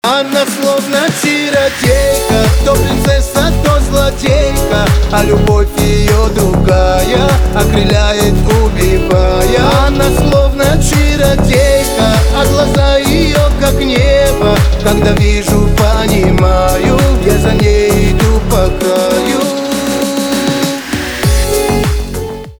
поп
битовые , чувственные